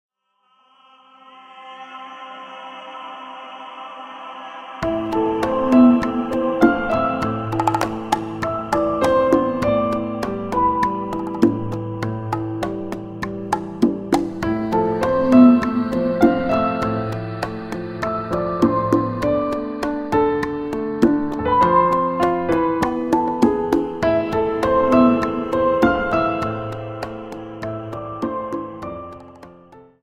Dance: Rumba 51